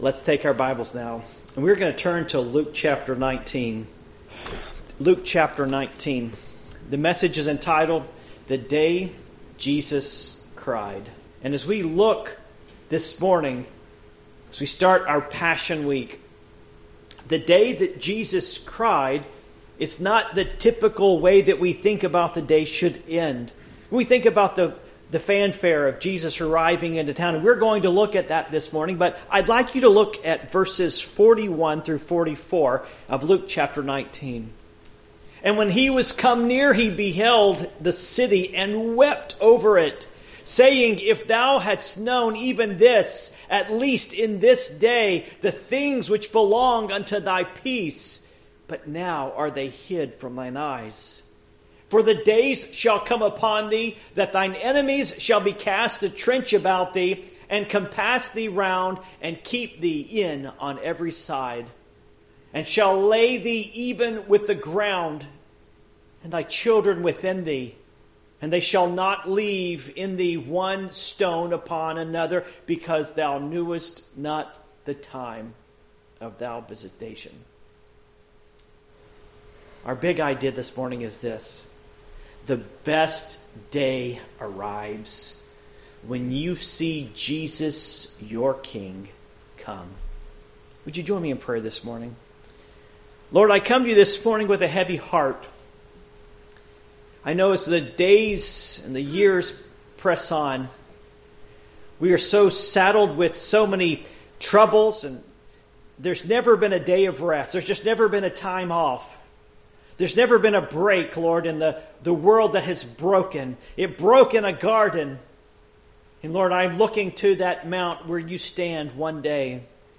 Passage: Luke 19:41-44 Service Type: Morning Worship